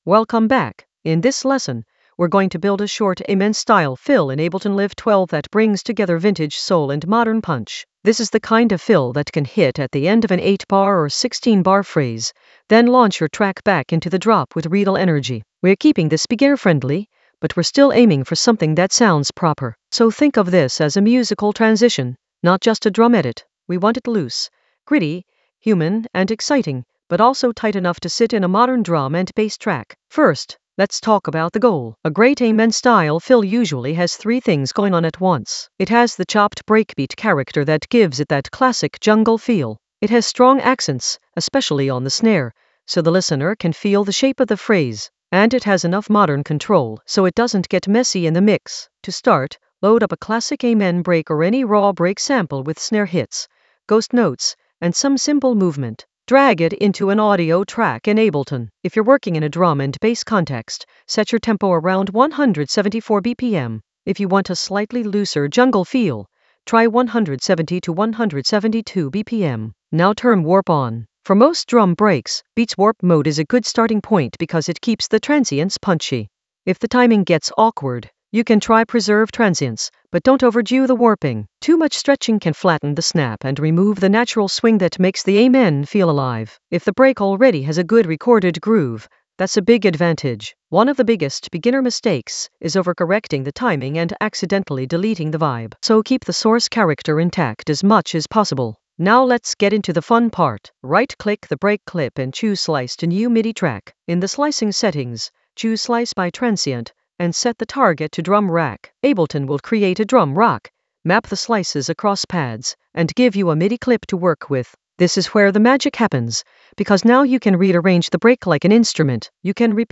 Narrated lesson audio
The voice track includes the tutorial plus extra teacher commentary.
An AI-generated beginner Ableton lesson focused on Arrange an Amen-style fill with modern punch and vintage soul in Ableton Live 12 in the Composition area of drum and bass production.